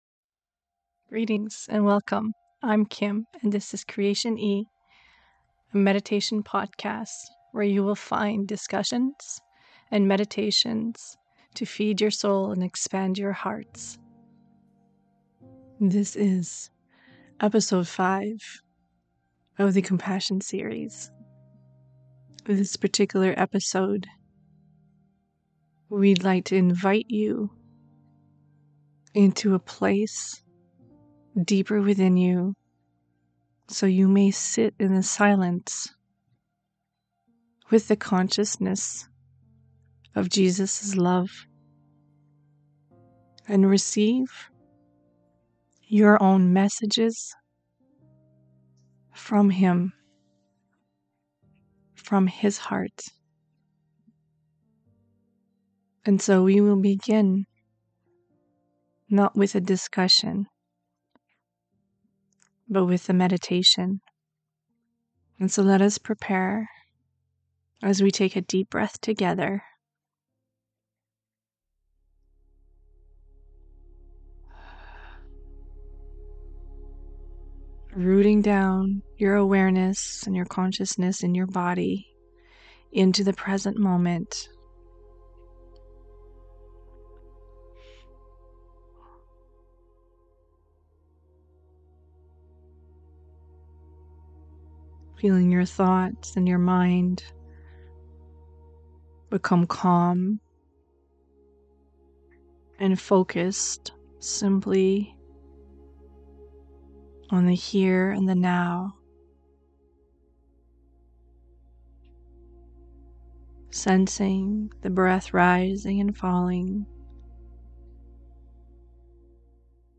In this episode we invite you to sit within the silence of your heart to attune your heart with the cosmic heart of Christ Love. This is a meditation only with the consciousness of Jesus as he offers us some gentle reminders of the importance of expanding compassion within our lives.&nbsp